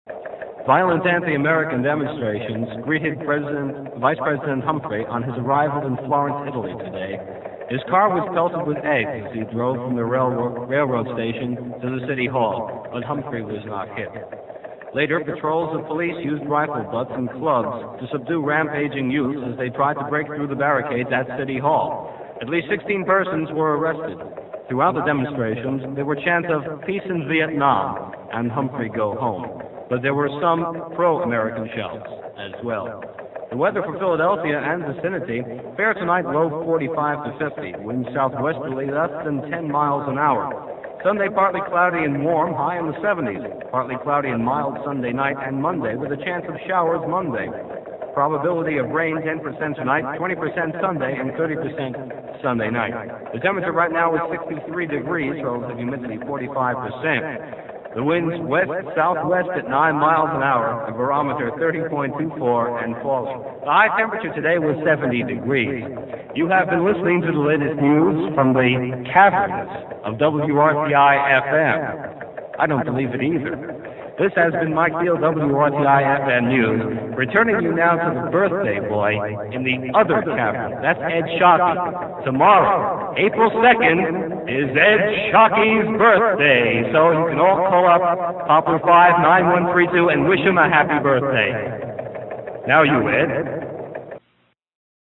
And an echo developed! Here's end of the newscast.